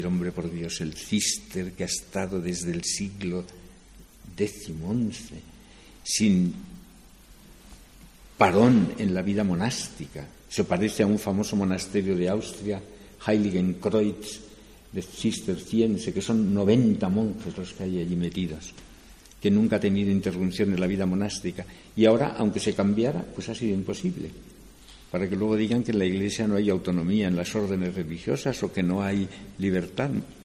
Monseñor César Franco, obispo de Segovia, sobre la marcha del Císter